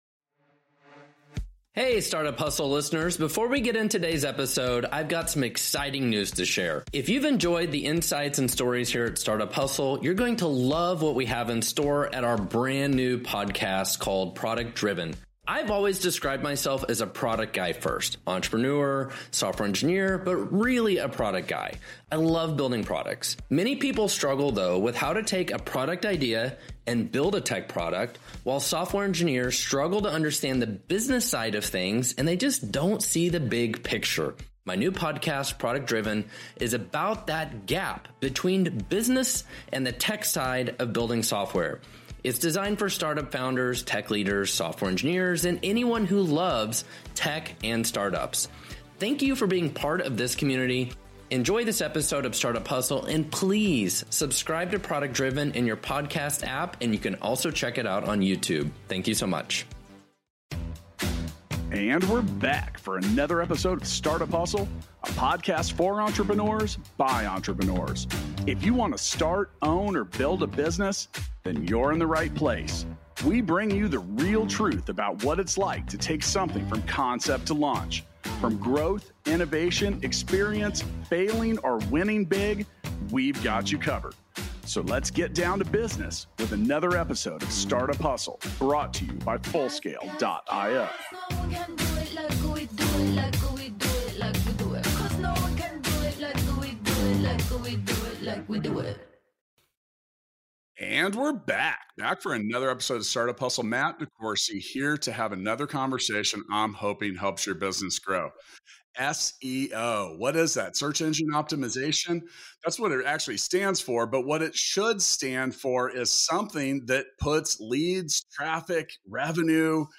for a conversation around turning your hard-earned search engine traffic into actual sales leads for your business. Learn all about the basics of SEO and best practices to consider for turning your website visitors into valuable clients.